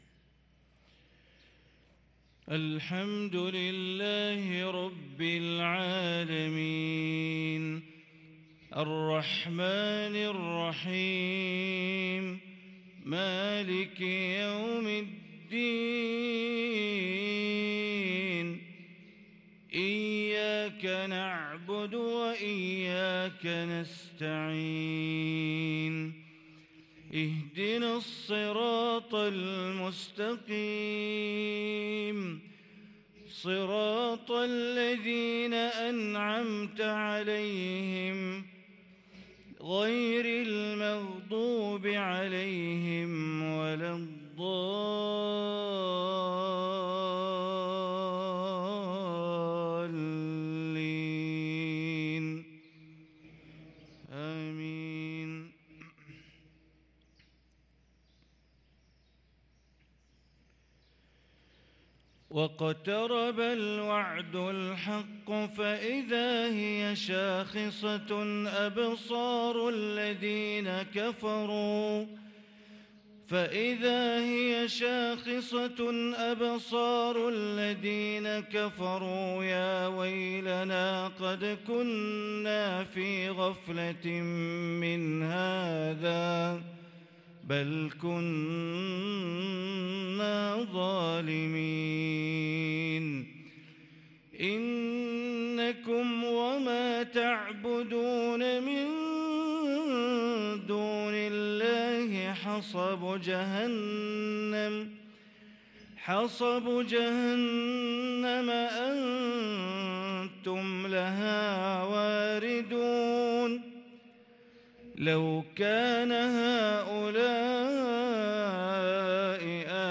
صلاة الفجر للقارئ بندر بليلة 14 صفر 1444 هـ
تِلَاوَات الْحَرَمَيْن .